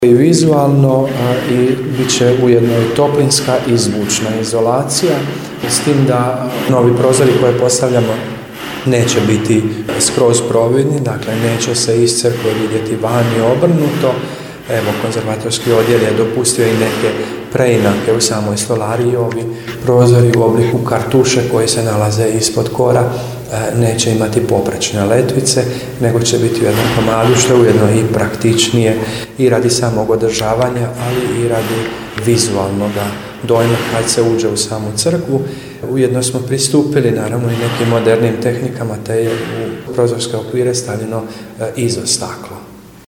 U akustičnom prostoru i bušilica nekako melodično zvuči, tako da je upravo taj radni prostor unutar crkve bio teren s kojeg prenosimo riječi, zvukove, radnu energiju…